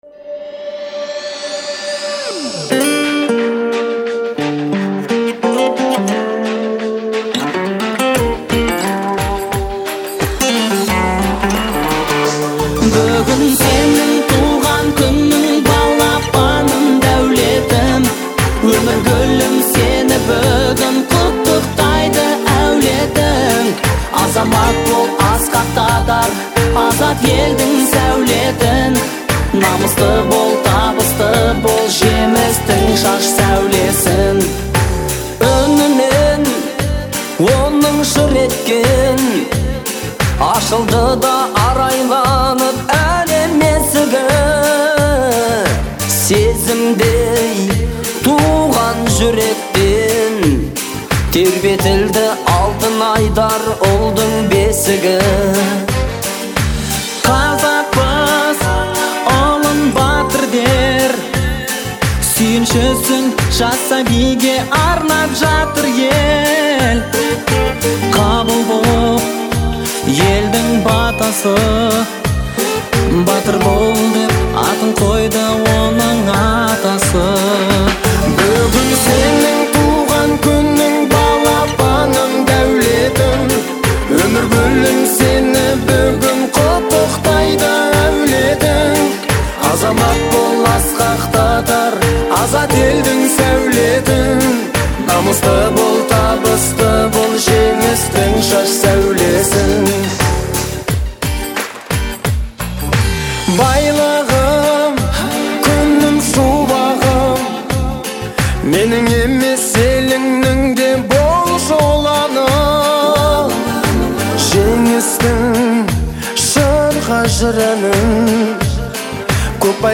выполненная в жанре казахского фольклорного попа.